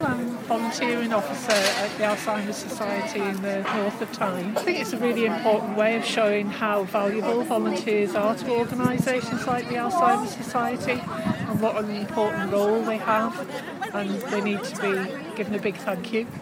One hundred volunteers from Gateshead and Newcastle joined hands on Gateshead Millennium Bridge this week to mark 30 years of Volunteers’ Week.